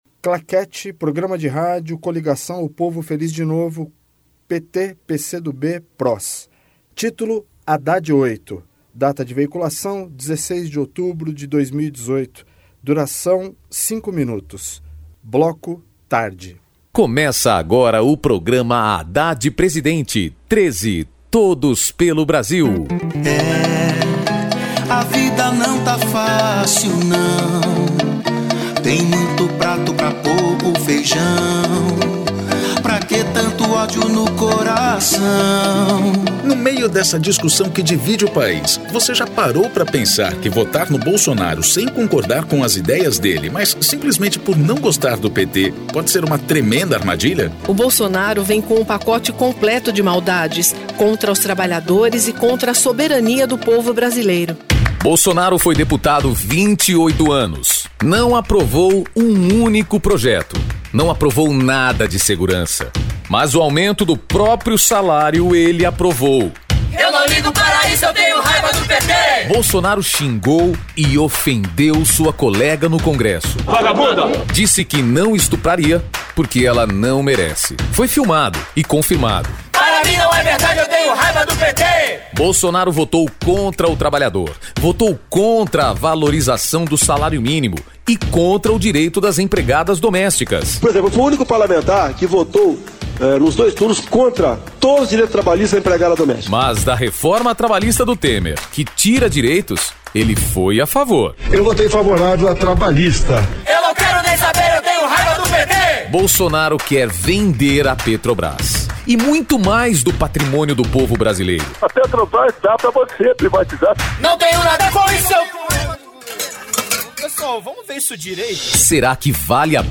Gênero documentaldocumento sonoro
Descrição Programa de rádio da campanha de 2018 (edição 38), 2º Turno, 16/10/2018, bloco tarde